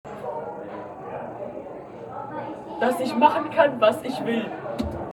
MS Wissenschaft @ Diverse Häfen